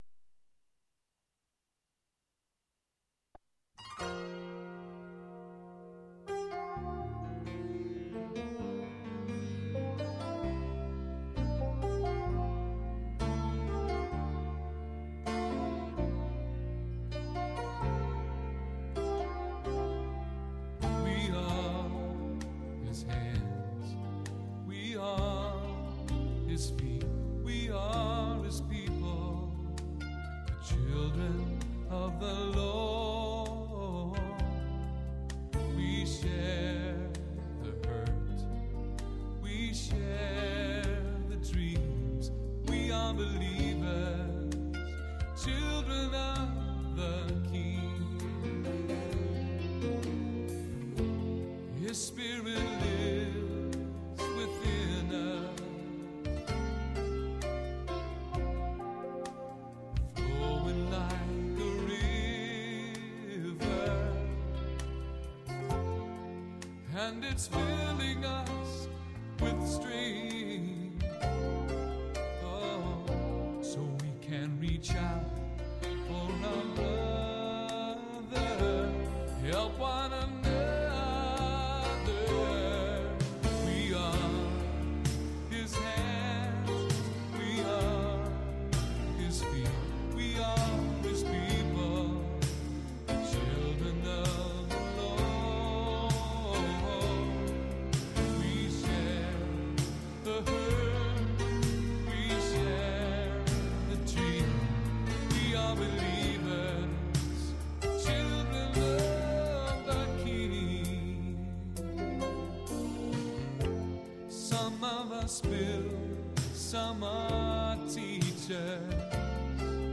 Contemporary Christian Music pop-rock band